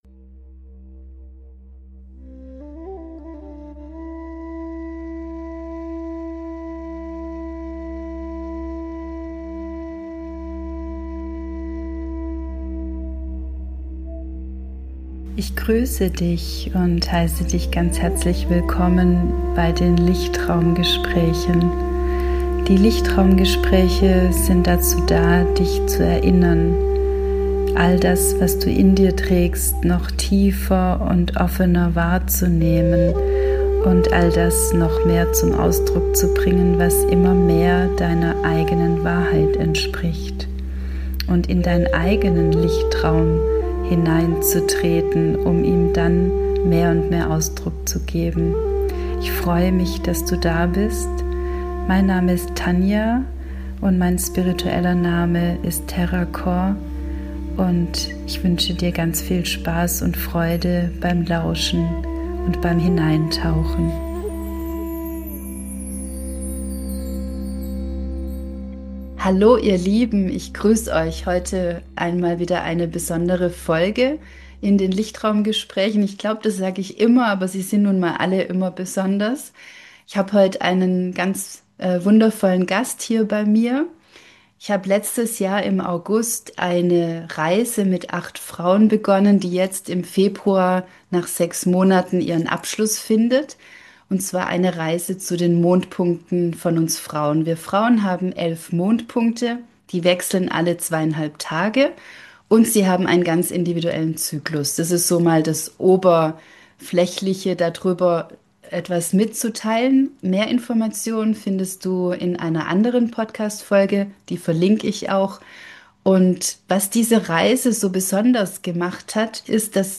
#72 - Interview